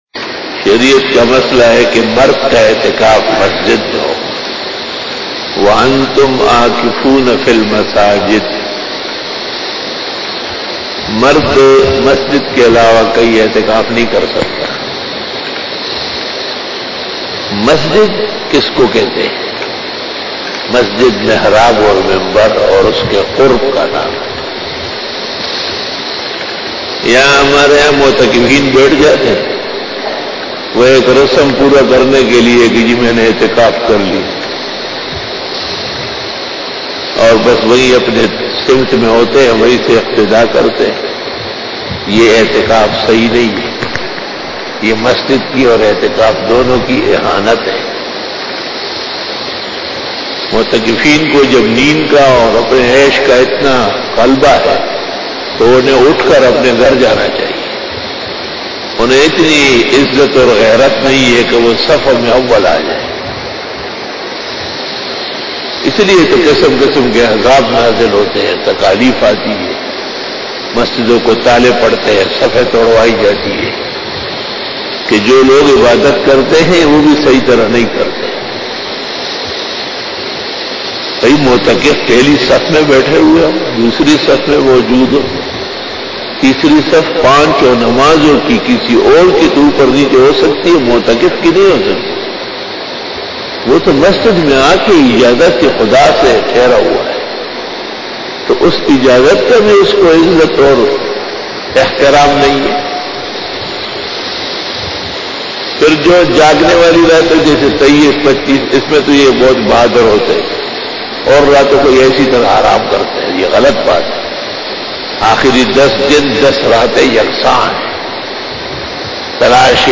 AITEKAF KA BAYAN 2
After Fajar Byan
بیان بعد نماز فجر